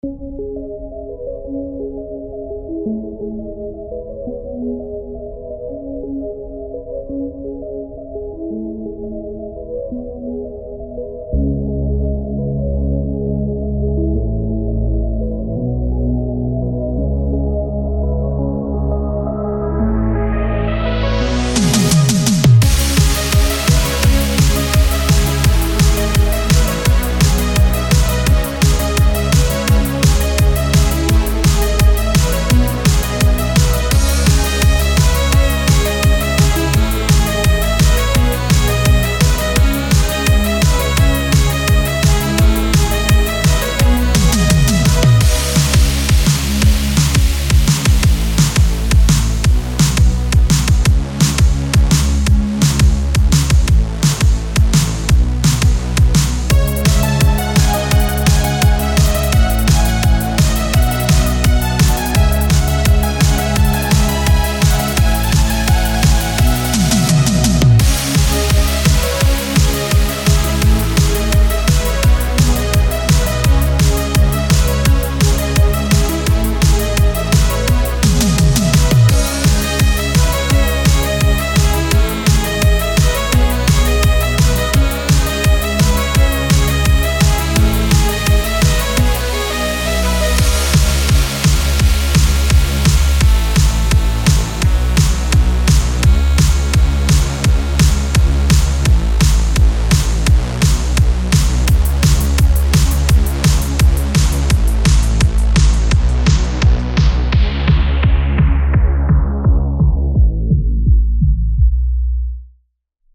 synthwave_type
My first time try to make synthwave xd
synth_type_1.mp3